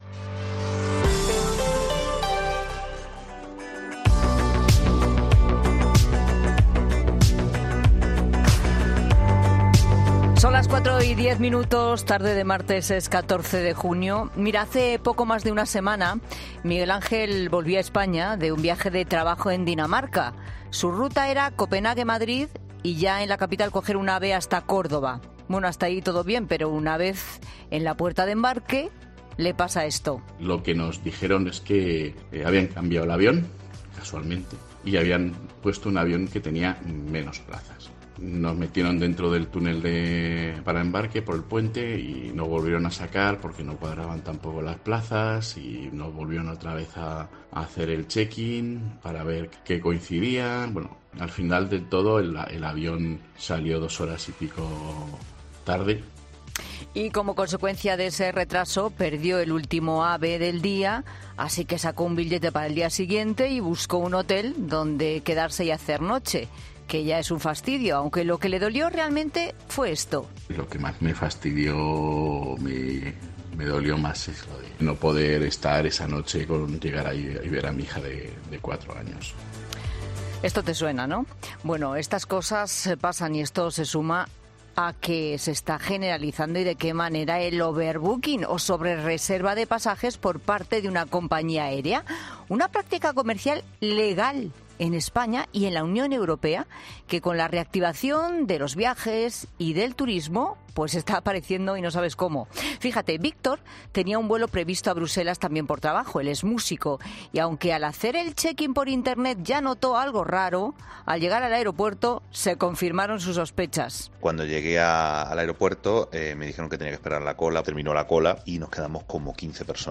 En 'La Tarde' hemos escuchado testimonios de personas que, por un overbooking, no llegaron a tiempo de alguna celebración familiar, o perdieron un día de trabajo o un siguiente avión con el que debían conectar en un determinado aeropuerto de la ruta de su viaje.